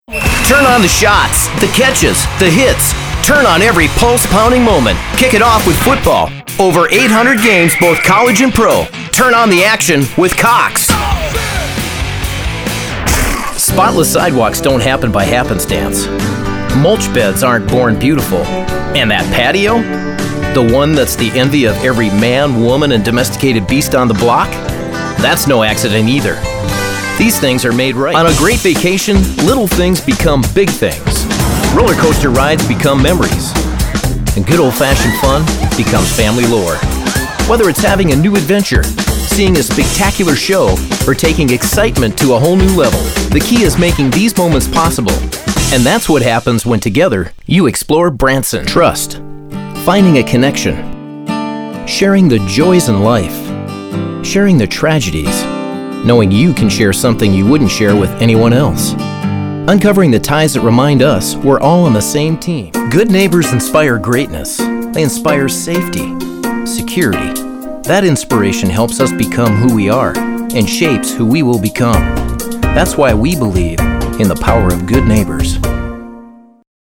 Male
English (North American)
Adult (30-50)
I have an age neutral tone and versatile delivery that has a young sound, energy and drive that will get your message through to the consumer!
Narration
Radio Commercials
Words that describe my voice are young, friendly.